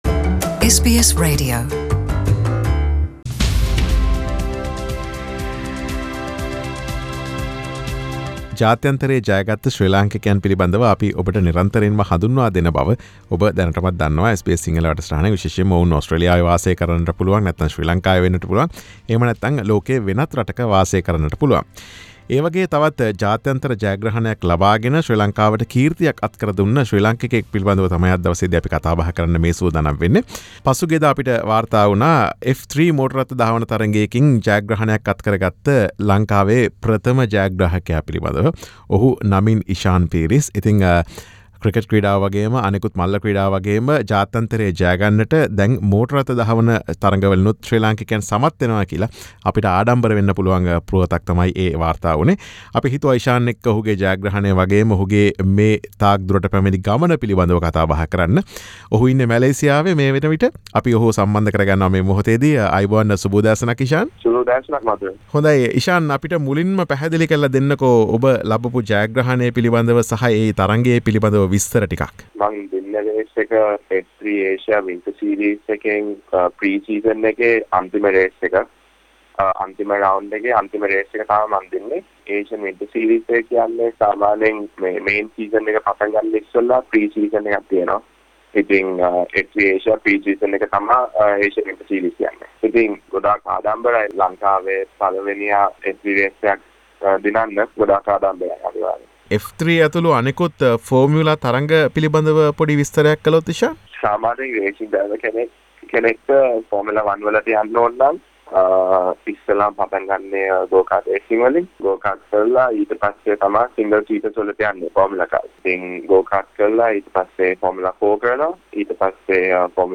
ඔහුගේ මෙම ජයග්‍රහණය සහ ඔහුගේ ක්‍රීඩා දිවිය පිළිබඳ SBS සිංහල ගුවන් විදුලිය සිදුකළ සාකච්ඡාවකි මේ.